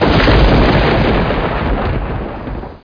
SND_EXPLOSION2.mp3